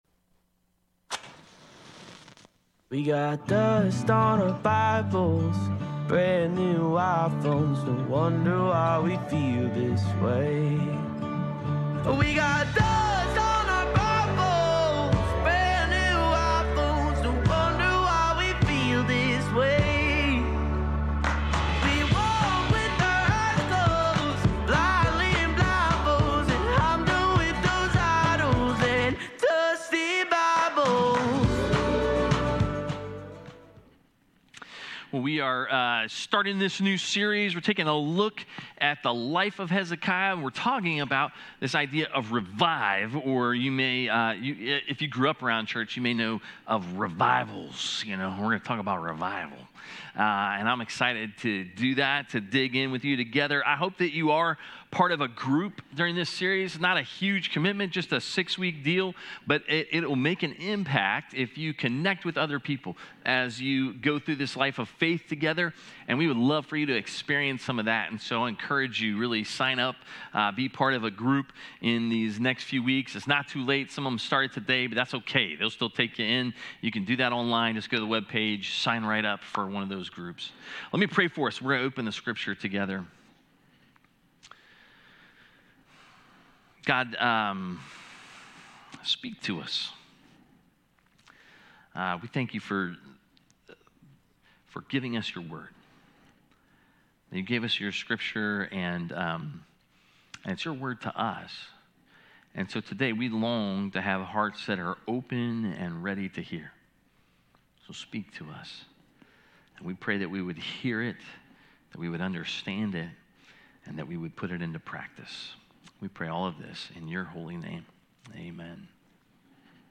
A message from the series "Revive."